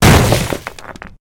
debris2.ogg